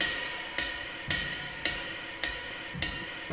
RIDE_LOOP_4.wav